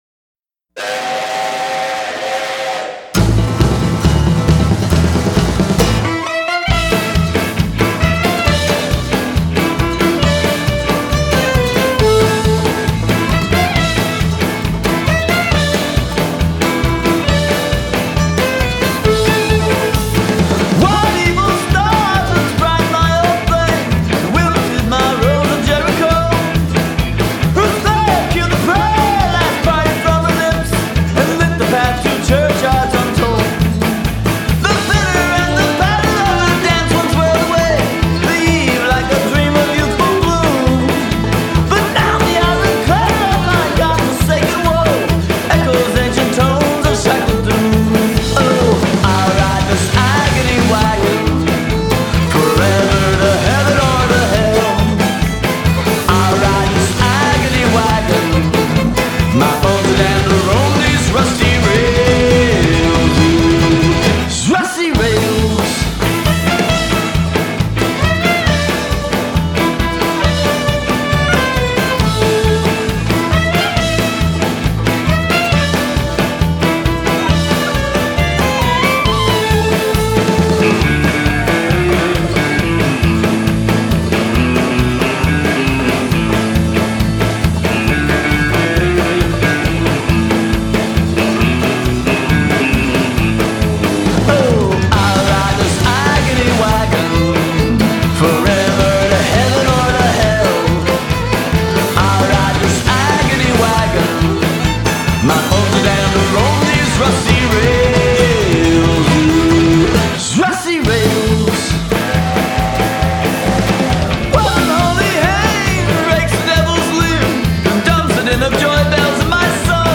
It has everything from Americana to Swamp Blues.